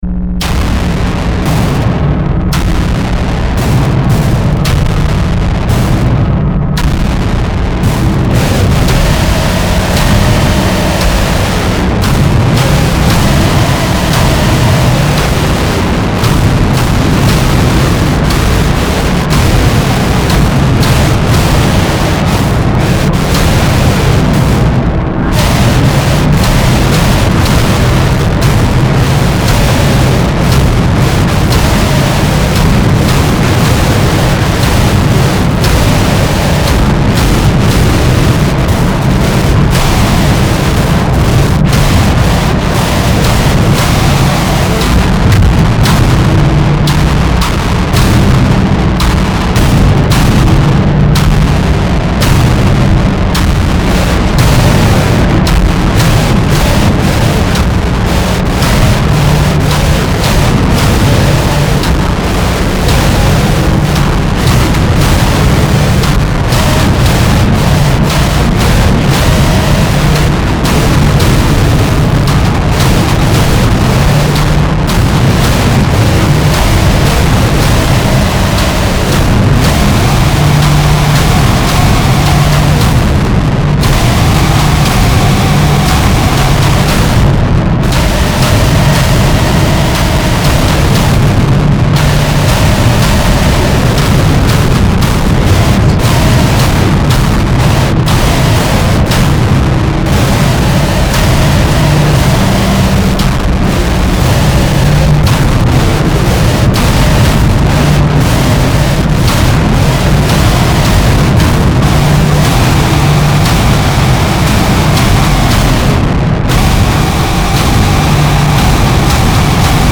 Harsh Noise/Power Electronics